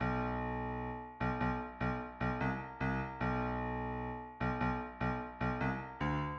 Looky Here Piano 150 BPM
Tag: 150 bpm Trap Loops Piano Loops 1.08 MB wav Key : Unknown